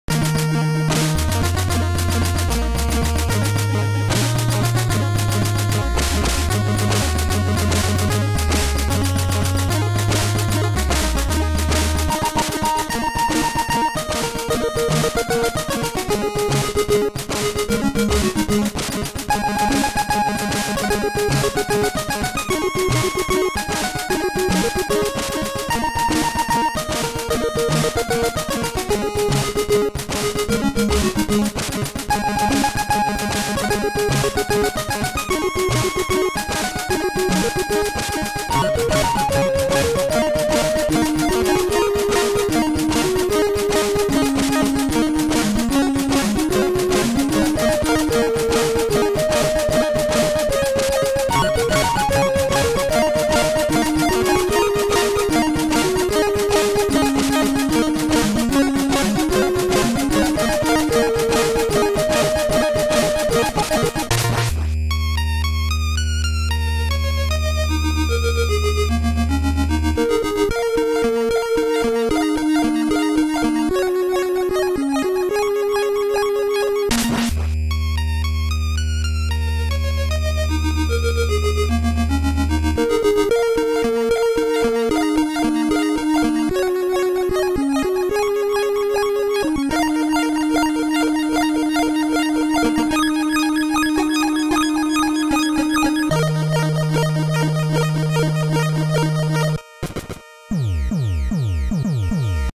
chiptune